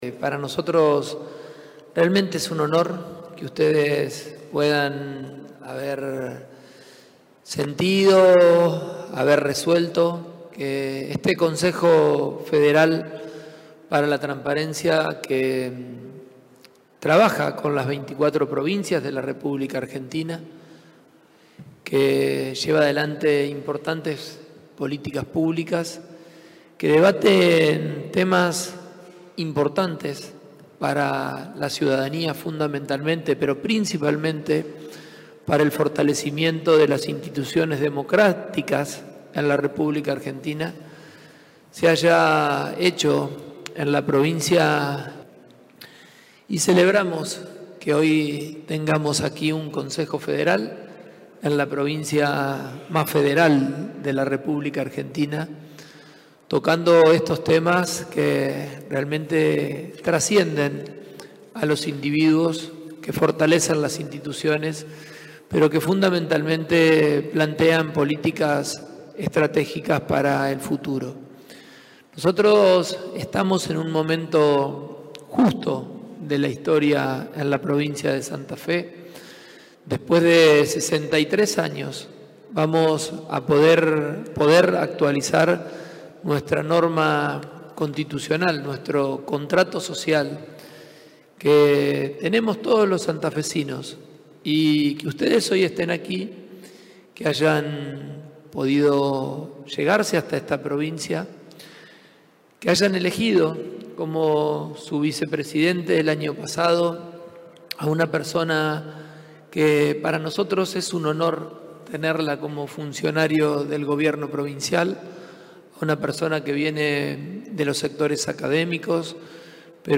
Lo afirmó el gobernador durante la Asamblea del Consejo Federal para la Transparencia, realizada en Santa Fe.
El acto tuvo lugar en el Salón Blanco de Casa de Gobierno y contó también con la participación del ministro de Gobierno e Innovación Pública, Fabián Bastia; la presidenta del CFT y titular de la Agencia de Acceso a la Información Pública, Beatriz Anchorena; el vicepresidente del CFT y subsecretario de Lucha Contra la Corrupción, Transparencia y Ética del Sector Público de la provincia, Marcelo Trucco; y representantes de todas las provincias vinculados a políticas de transparencia y acceso a la información.
Fragmento de la exposición del Gobernador